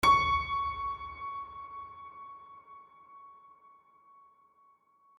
piano-sounds-dev
HardPiano